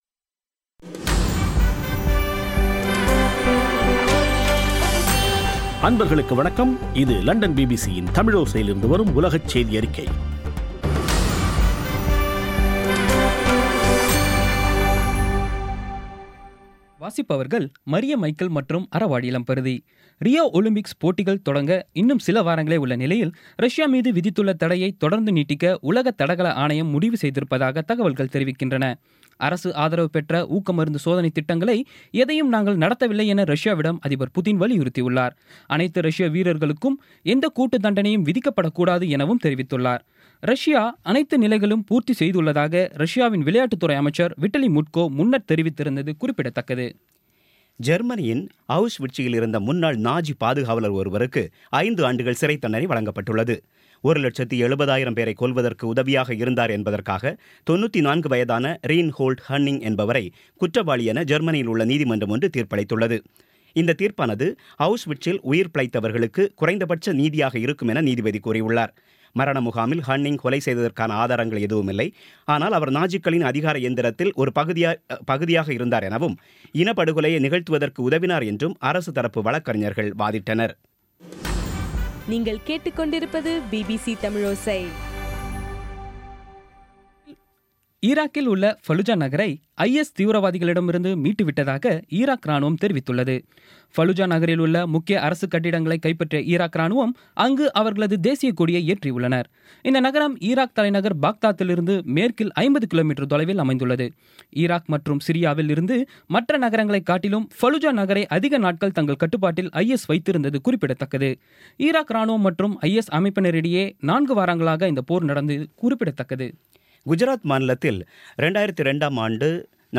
இன்றைய (ஜூன் 17ம் தேதி ) பிபிசி தமிழோசை செய்தியறிக்கை